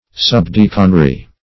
Search Result for " subdeaconry" : The Collaborative International Dictionary of English v.0.48: Subdeaconry \Sub*dea"con*ry\, Subdeaconship \Sub*dea"con*ship\, n. (Eccl.) The order or office of subdeacon.